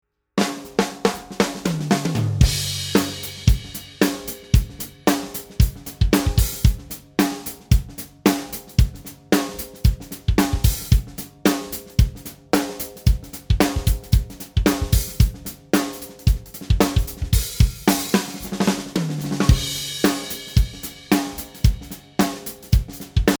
A-Cooper: 14m2 x 3m room size.
Here you have a few audios with non-processed drums, to listen the 3 different options you can choose.
We recorded these tracks with different tunings on the snare, but with the same drum kit: a Yamaha Hybrid Maple, sizes 14×6, 10, 12, 14, 20 pretty low tension on toms and floor tom, and softly muffled bass drum.
No EQ, no compression, no effects, no gates